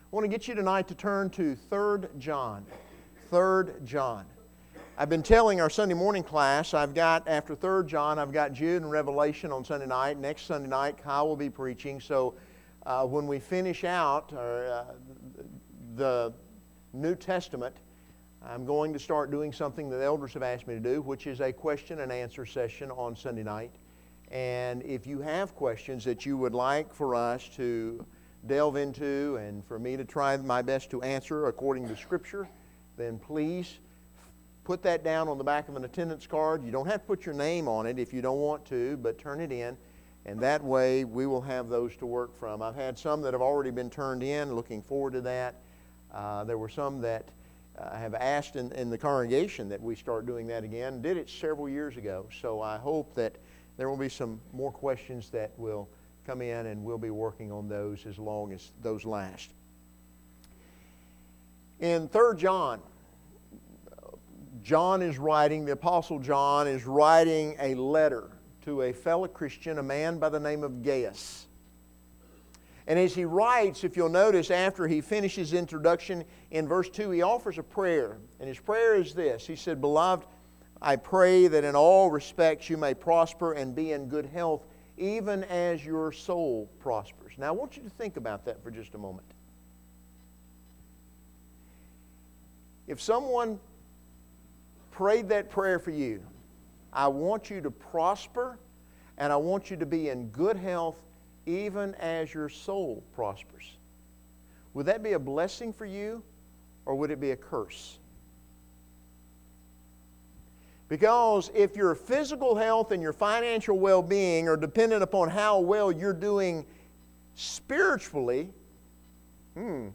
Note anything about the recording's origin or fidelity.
3 John 1:4 Service Type: Sunday Evening